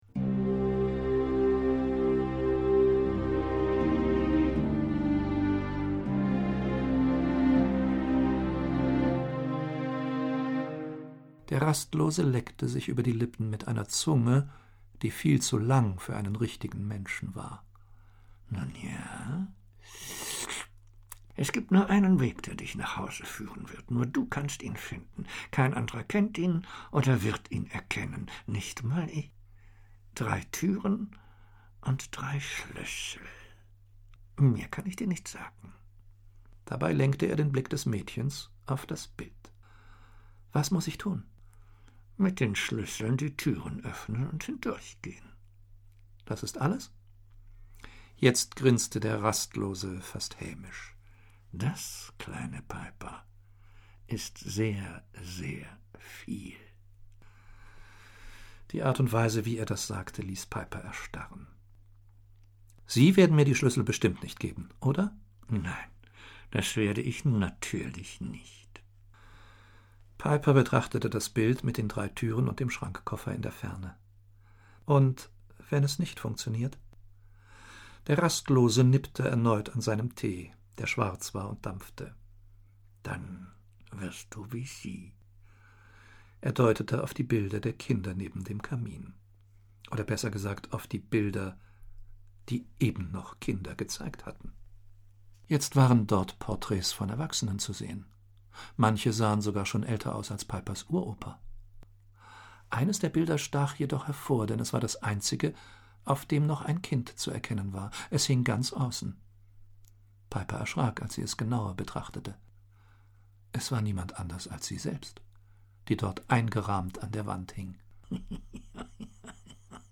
Hörbuch, 2 CD , ca. 150 Minuten